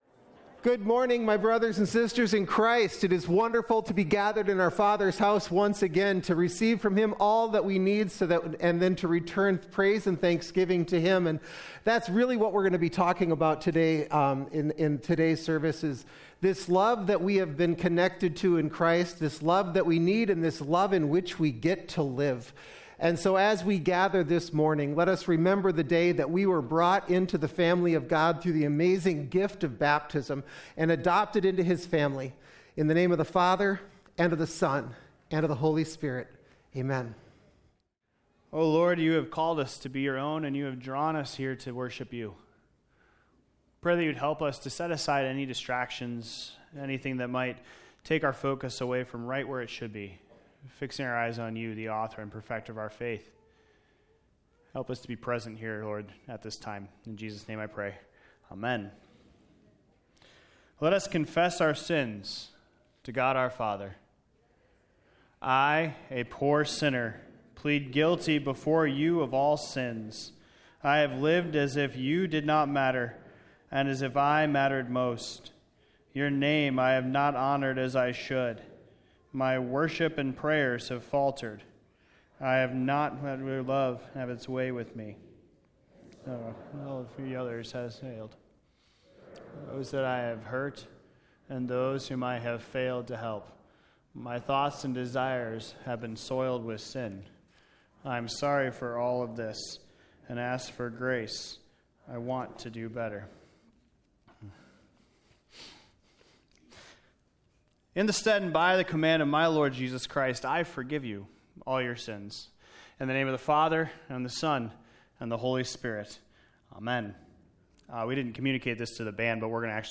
2024-May-5-Complete-Service.mp3